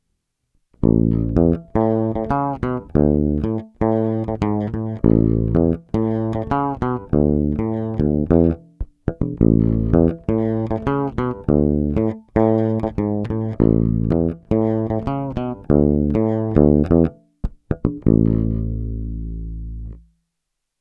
Není-li řečeno jinak, následující nahrávky jsou vyvedeny rovnou do zvukové karty, normalizovány a jinak ponechány bez dalších úprav.
Následující tři ukázky jsou hrány s korekcemi ve střední poloze a vždy nad aktivním snímačem, v případě obou pak mezi nimi.
Snímač u kobylky